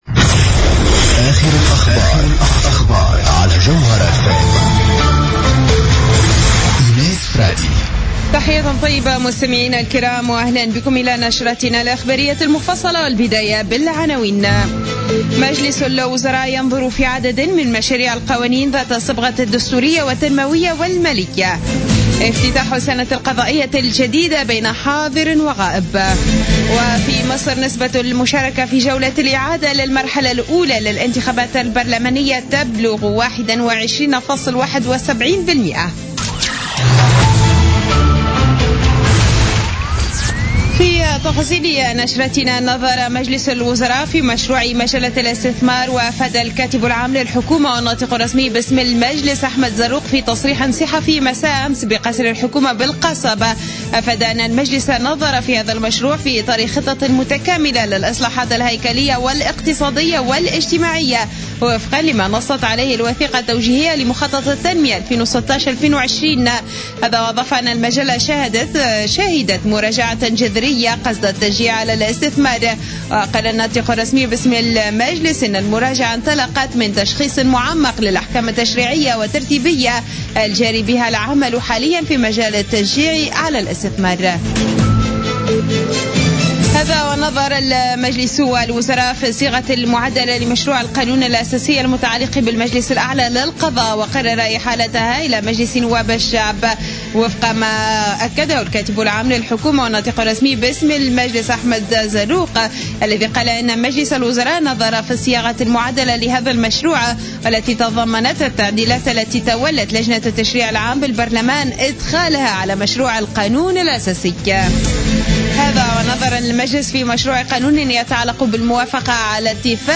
Journal Info 00:00 du samedi 31 octobre 2015